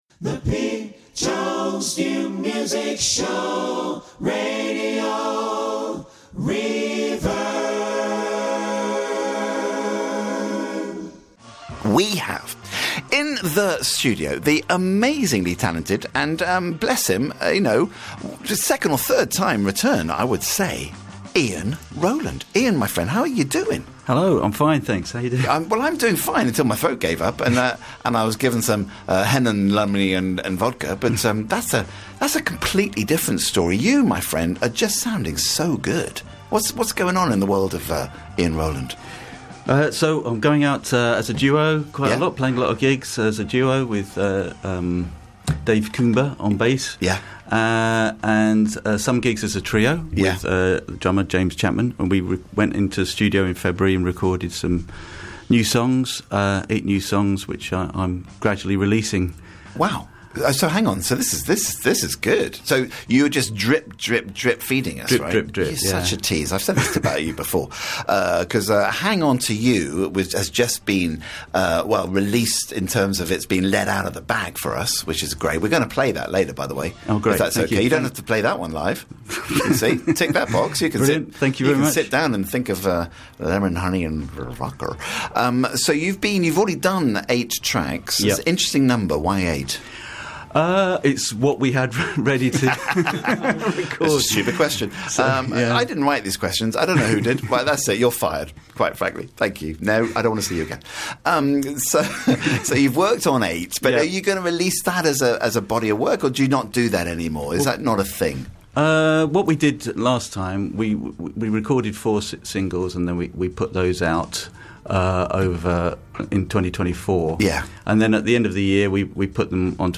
2 live tracks and the last single played out.
(studio recording)